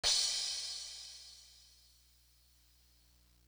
Marathon Crash.wav